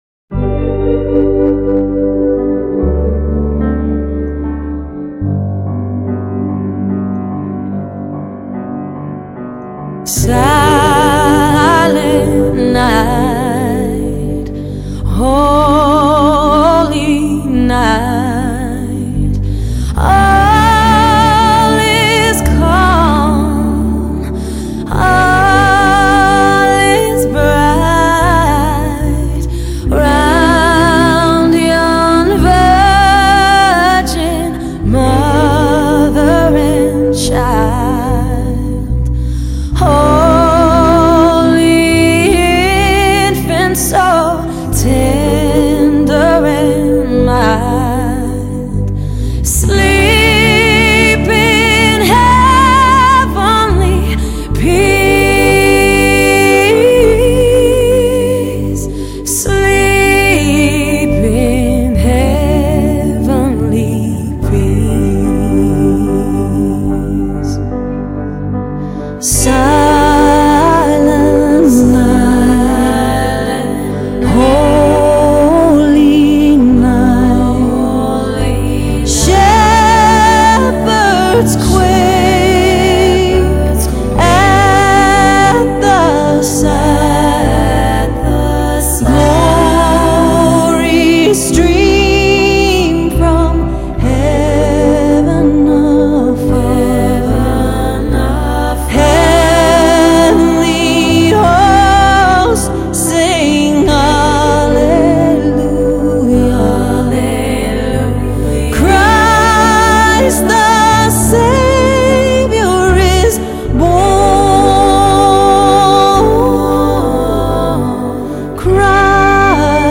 类　　别: Pop, Jazz, Christmas　　　　　.